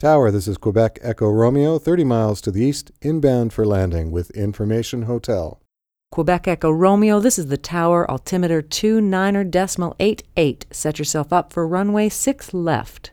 airTrafficControl.wav